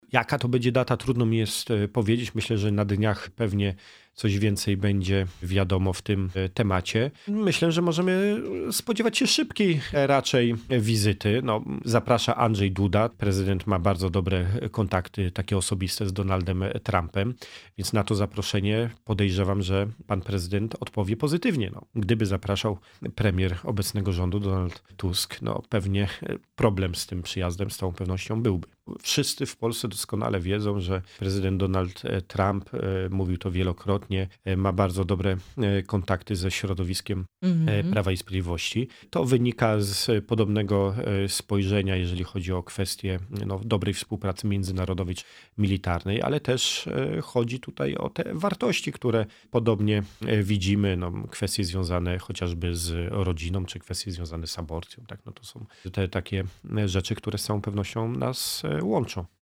Te tematy poruszyliśmy z „Porannym Gościem” – posłem na Sejm Pawłem Hreniakiem, szefem struktur PiS w okręgu wrocławskim.